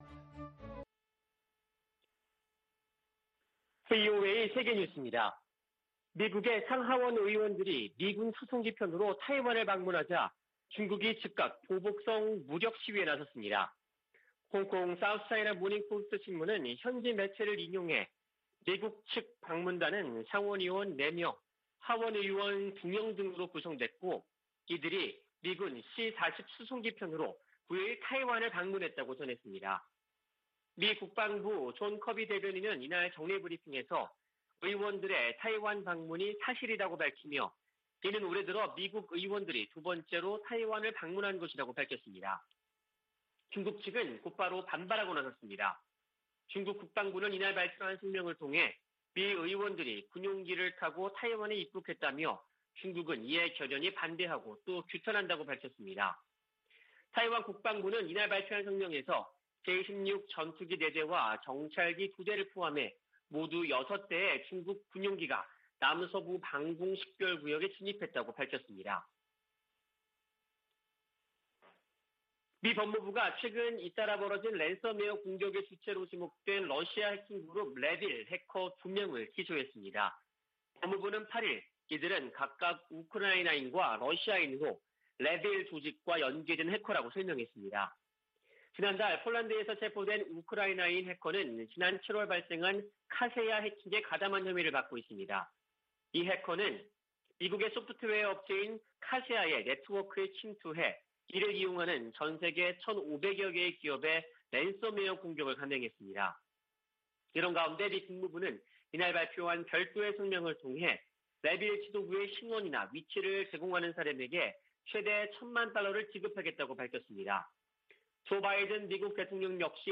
VOA 한국어 아침 뉴스 프로그램 '워싱턴 뉴스 광장' 2021년 11월 10일 방송입니다. 미국 민주당 상·하원 의원들이 대북 인도적 지원을 위한 규정 완화를 촉구하는 서한을 조 바이든 대통령에게 보냈습니다. 일본은 북 핵 위협 제거되지 않은 상태에서의 종전선언을 우려하고 있을 것으로 미국의 전문가들이 보고 있습니다. 최근 북-중 교역이 크게 증가한 가운데 코로나 사태 이후 처음으로 열차가 통행한 것으로 알려졌습니다.